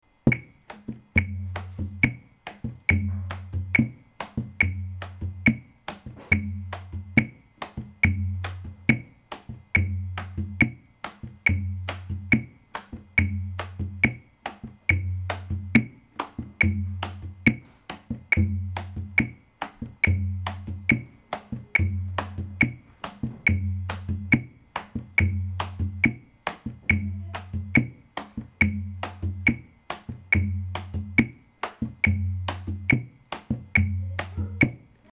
rebolo tempo=70
rebolo_70.mp3